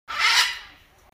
جلوه های صوتی
دانلود صدای پرنده 29 از ساعد نیوز با لینک مستقیم و کیفیت بالا